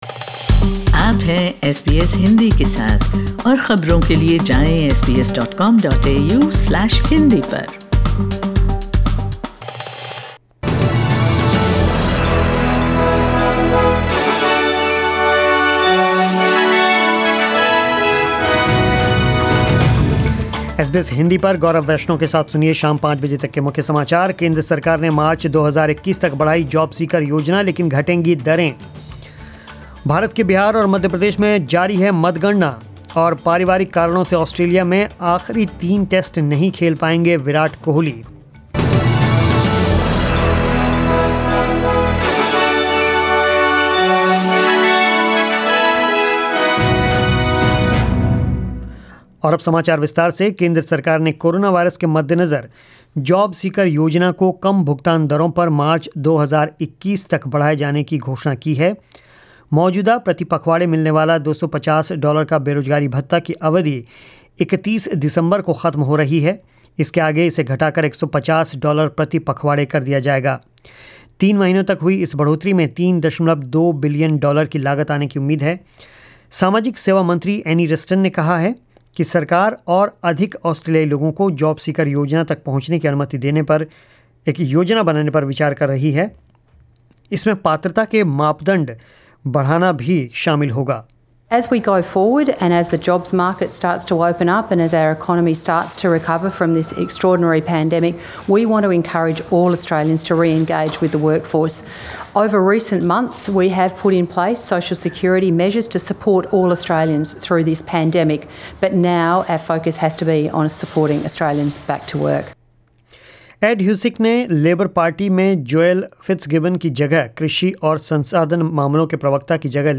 News in Hindi 10 November 2020